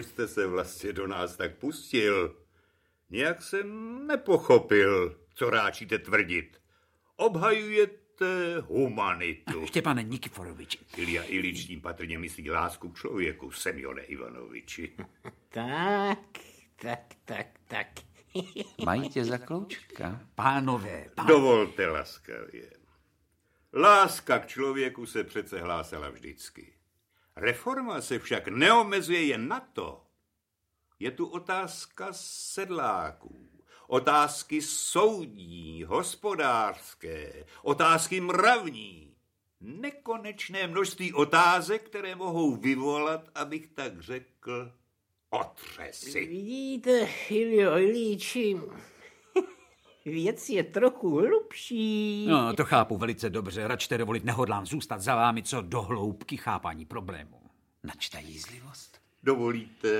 Audiobook
Read: Antonie Hegerlíková